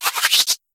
Catégorie:Cri Pokémon (Soleil et Lune) Catégorie:Cri de Sovkipou